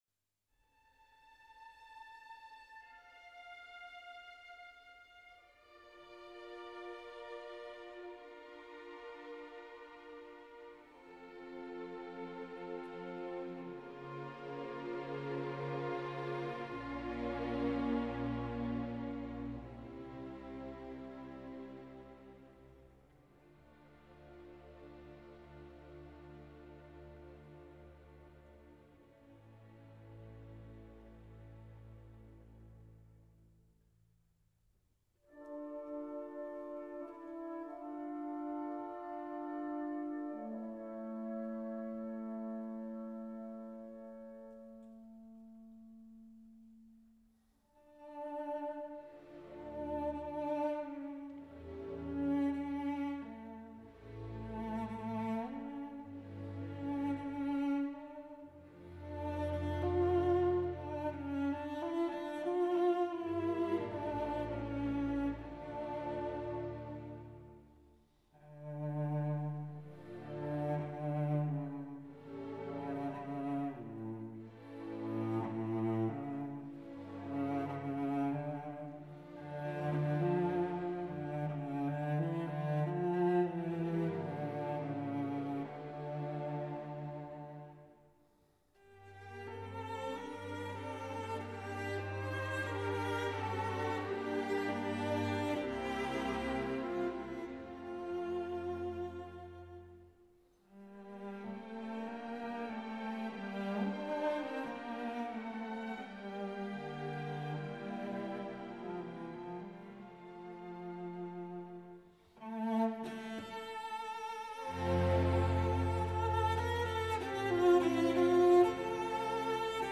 Op. 47, Adagio on Hebrew Melodies for Violoncello and Orchestra.